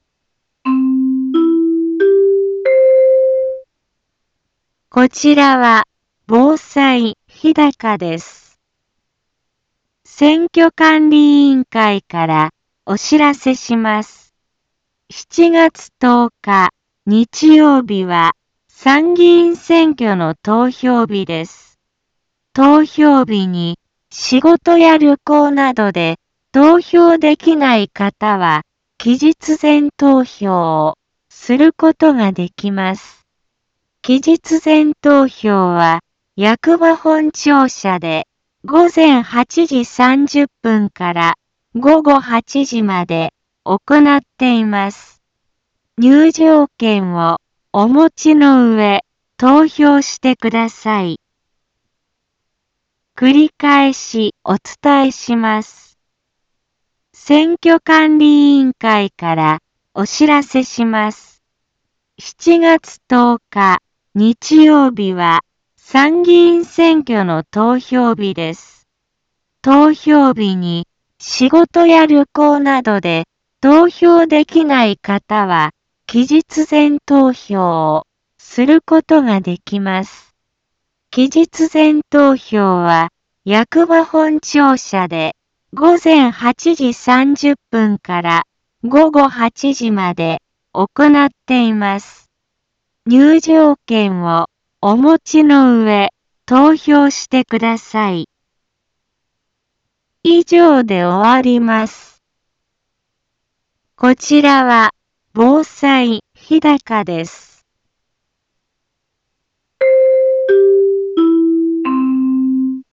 一般放送情報
Back Home 一般放送情報 音声放送 再生 一般放送情報 登録日時：2022-06-23 15:03:58 タイトル：参議院議員通常選挙投票棄権防止の呼びかけ インフォメーション：こちらは防災日高です。